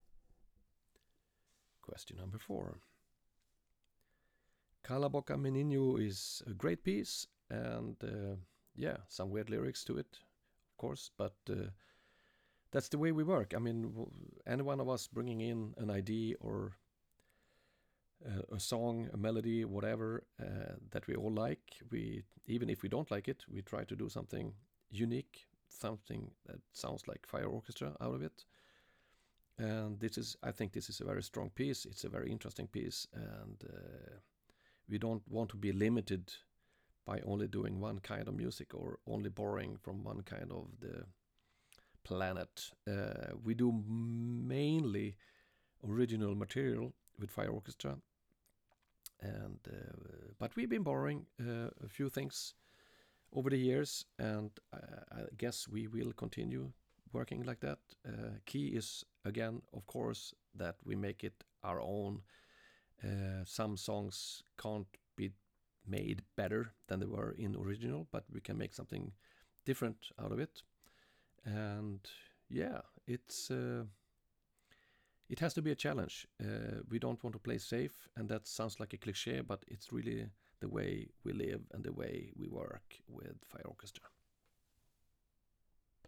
Big Band music can factually be overpowering.
Especially good for hot summer days!!!